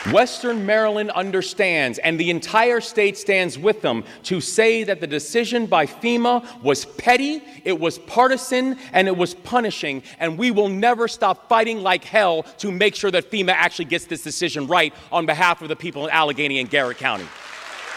Governor Wes Moore brought some fire in his speech to the Maryland Association of Counties over the weekend.